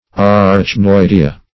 Arachnoidea \Ar`ach*noid"e*a\
arachnoidea.mp3